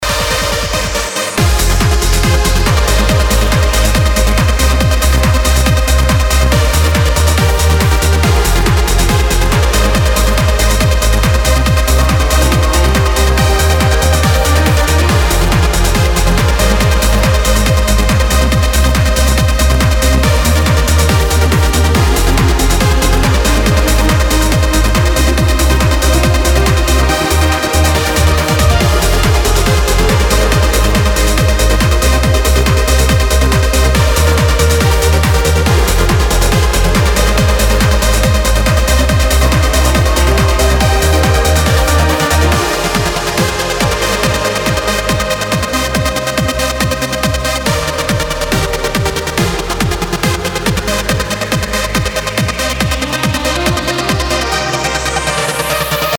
• Качество: 320, Stereo
громкие
без слов
пианино
Trance